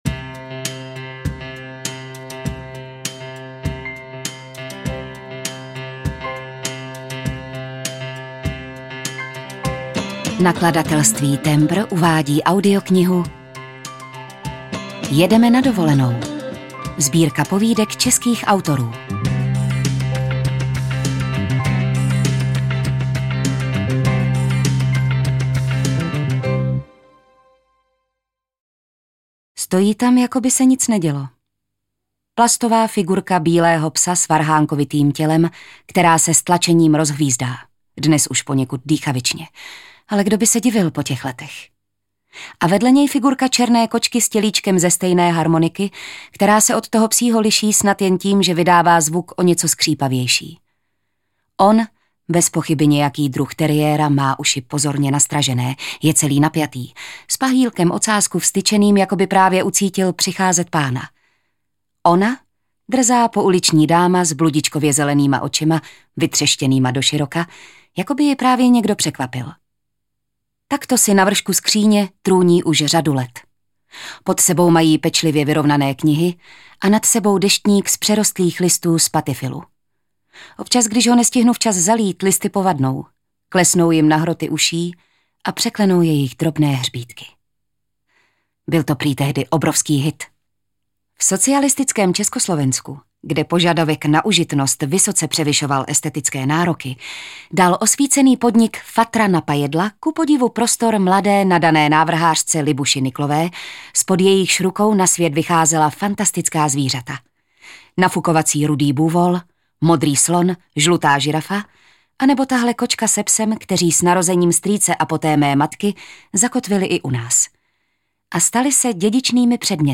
Jedeme na dovolenou audiokniha
Ukázka z knihy